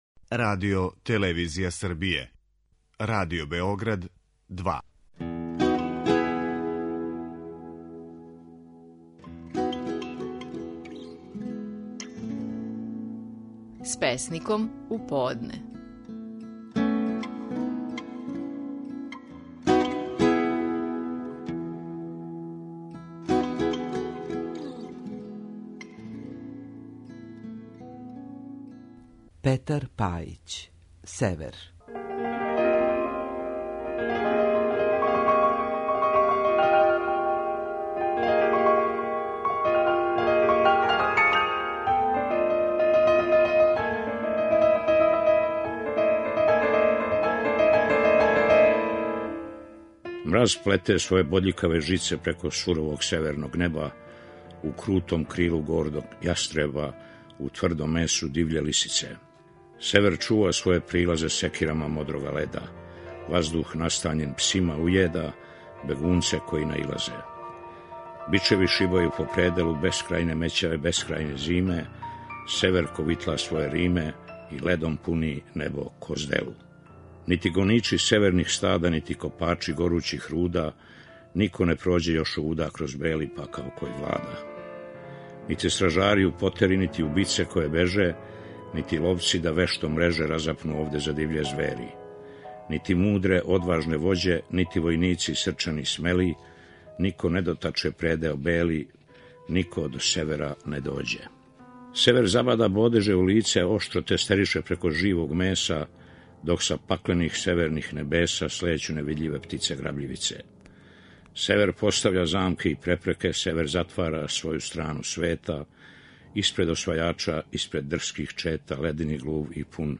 Стихови наших најпознатијих песника, у интерпретацији аутора.
Петар Пајић говори своју песму „Север".